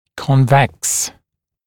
[ˌkɔn’veks][ˌкон’вэкс]выпуклый, выгнутый